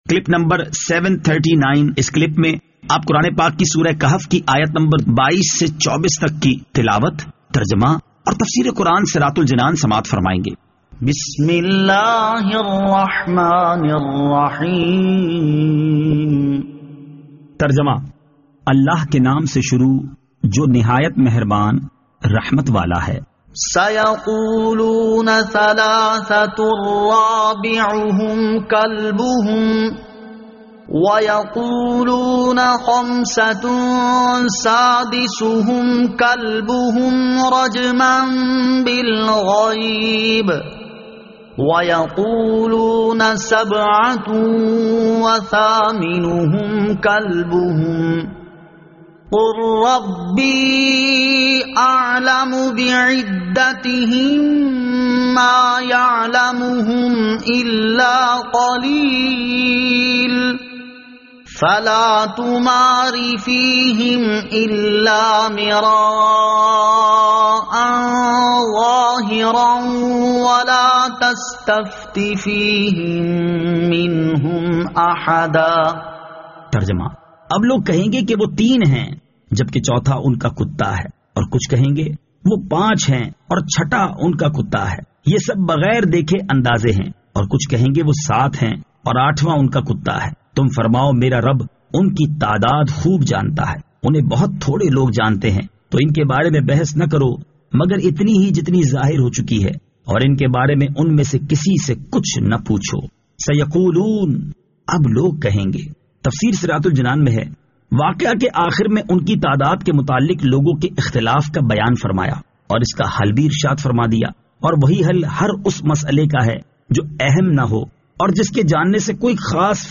Surah Al-Kahf Ayat 22 To 24 Tilawat , Tarjama , Tafseer
2021 MP3 MP4 MP4 Share سُوَّرۃُ الکَھْفِ آیت 22 تا 24 تلاوت ، ترجمہ ، تفسیر ۔